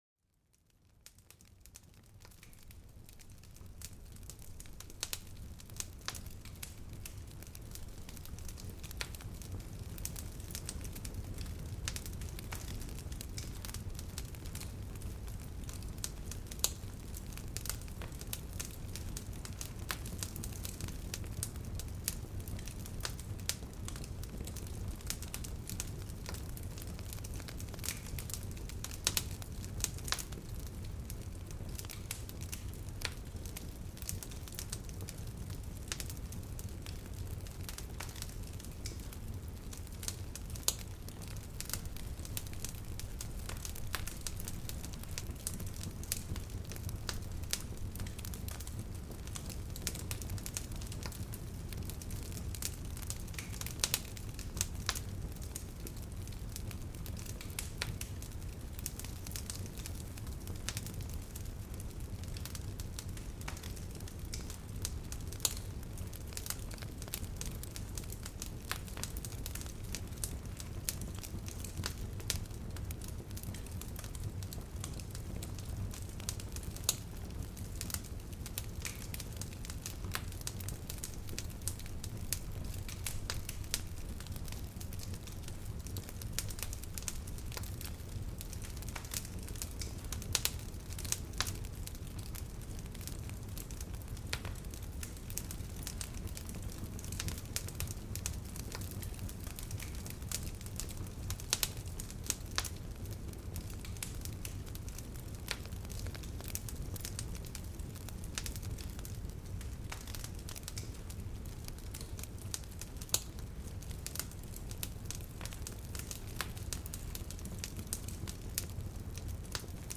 Sumérgete en el ambiente cálido de un fuego chisporroteante, donde cada chispa cuenta una historia y cada llama baila al ritmo de una melodía calmante. Descubre el poder relajante del fuego, capaz de reducir la ansiedad e invitar a la meditación y contemplación.